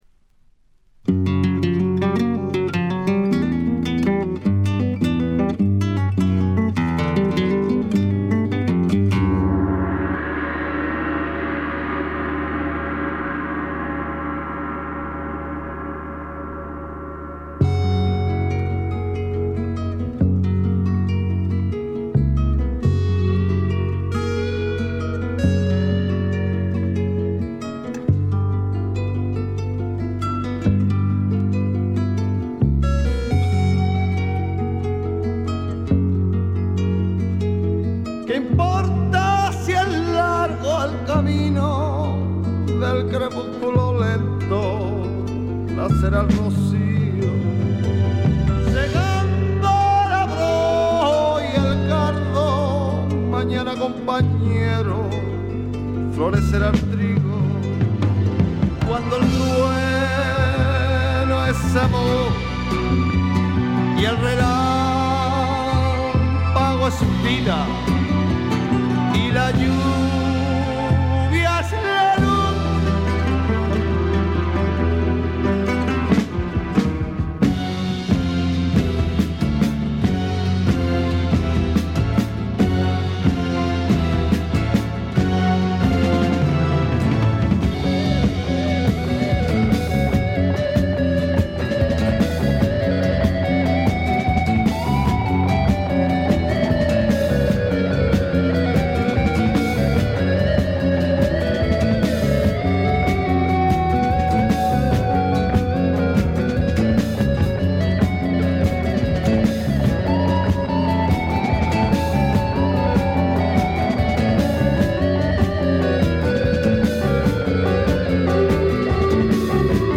ほとんどノイズ感無し。
試聴曲は現品からの取り込み音源です。
Classical Guitar [Guitarra Espa?ola]